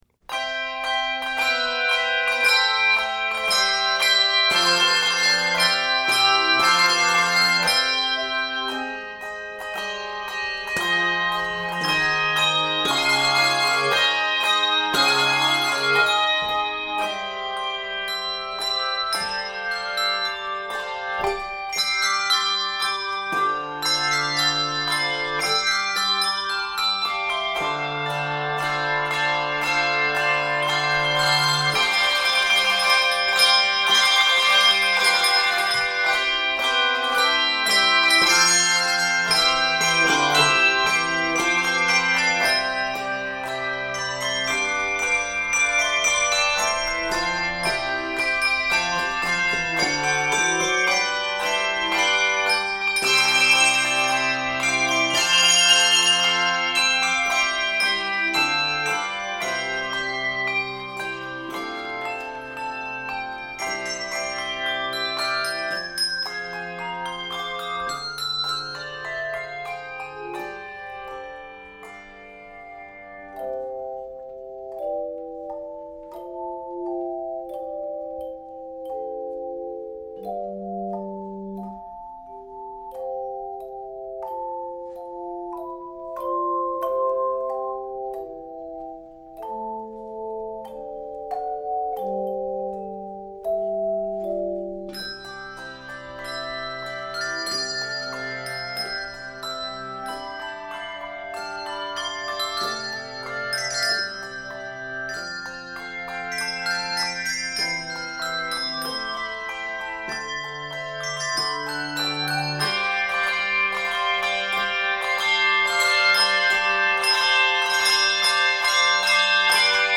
This festive and dignified setting
is filled with energy and Easter joy.